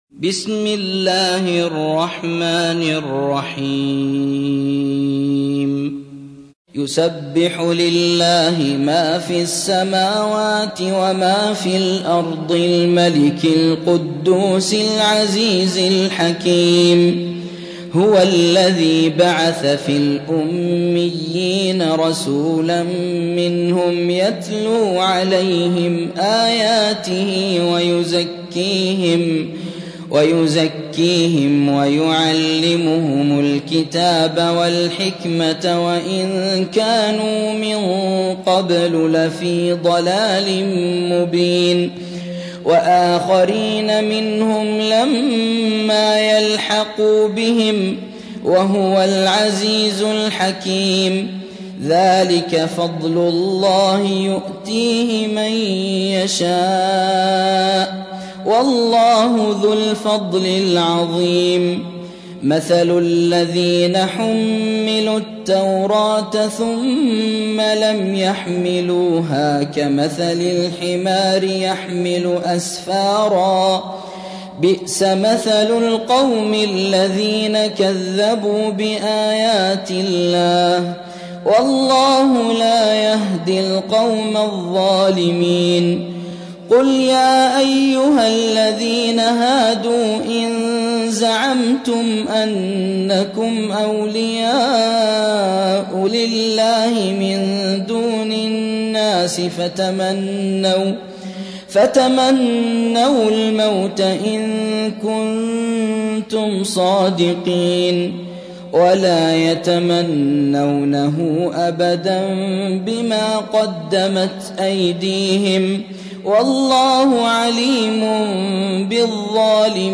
تحميل : 62. سورة الجمعة / القارئ يوسف الشويعي / القرآن الكريم / موقع يا حسين